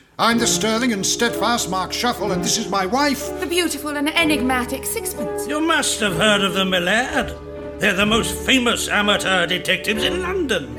Audio drama
Memorable Dialog